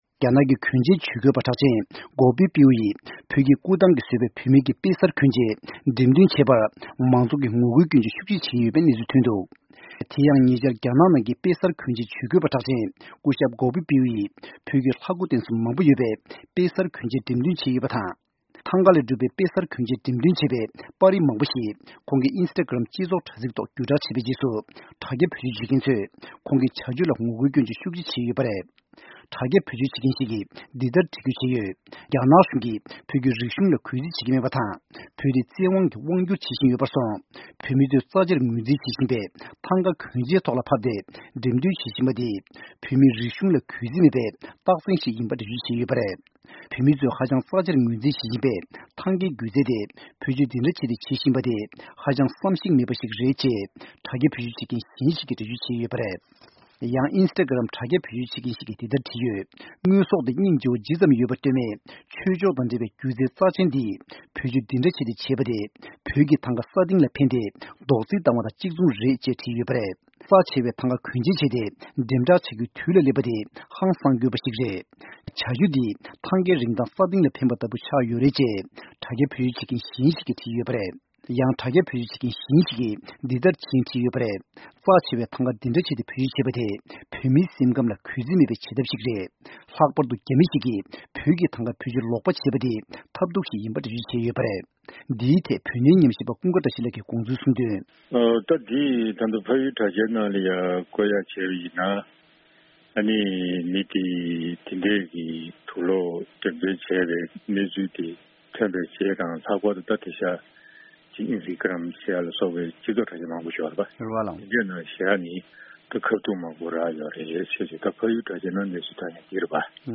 གནས་ཚུལ་སྙན་སྒྲོན